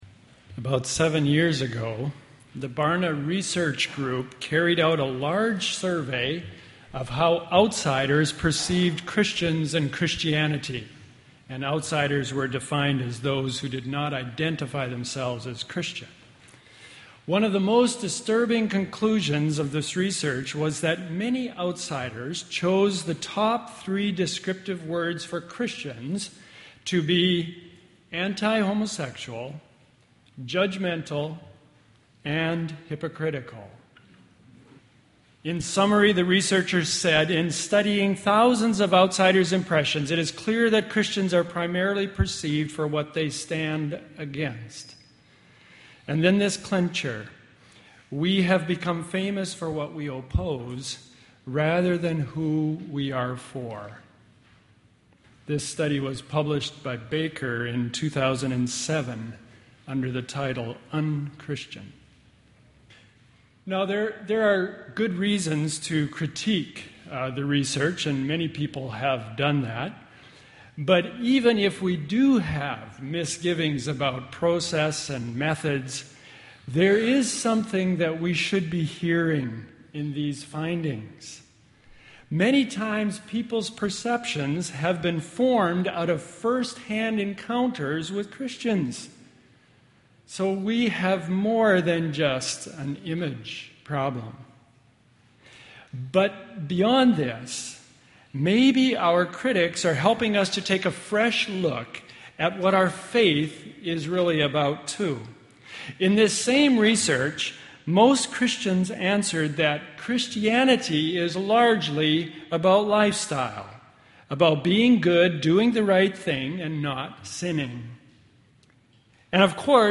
2013 Sermons Passing Judgment Play Episode Pause Episode Mute/Unmute Episode Rewind 10 Seconds 1x Fast Forward 30 seconds 00:00 / Subscribe Share RSS Feed Share Link Embed Download file | Play in new window